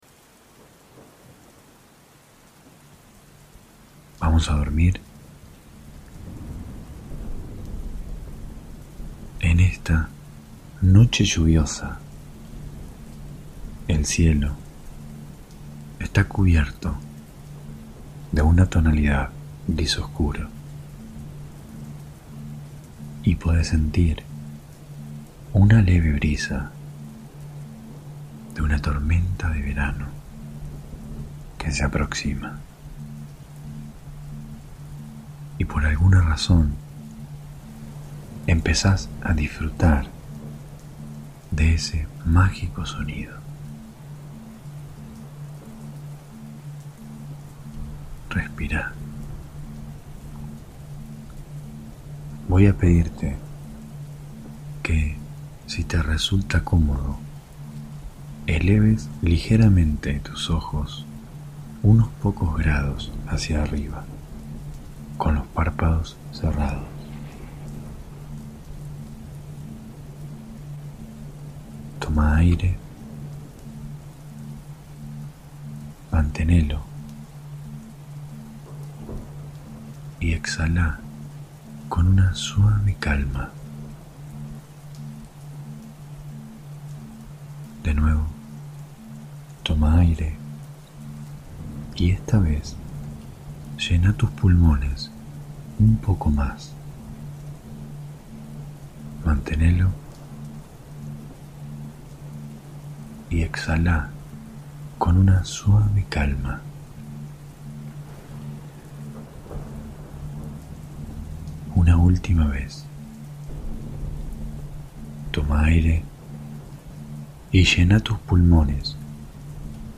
Hipnosis guiada para dormir. [Altamente recomendable escucharlo con auriculares ] Crédito: Omnity Meditación. Experiencias inmersivas para ayudarte a dormir profundamente.